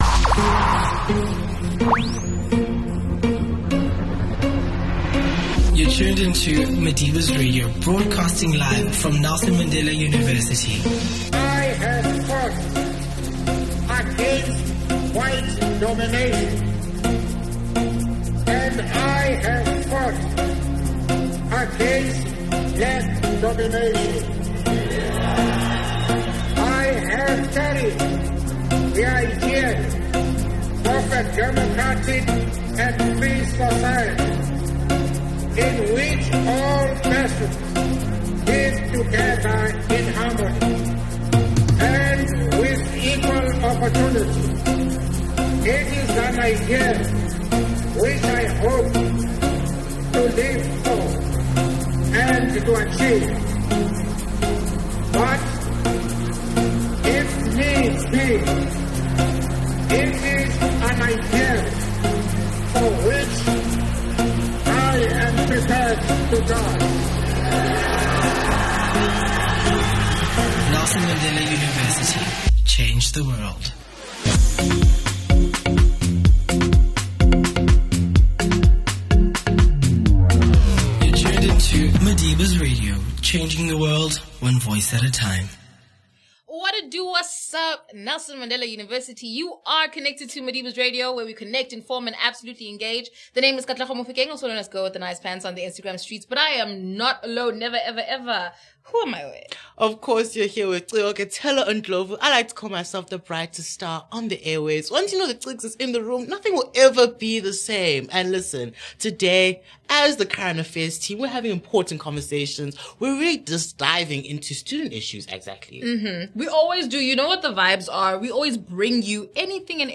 The interview talks about creating awareness and solidarity for the initiative that is aimed at drawing attention to the realities of student hunger within our university community. The initiative seeks not only to raise awareness, but also to inspire meaningful engagement, encourage collective action, and strengthen support for students affected by food insecurity.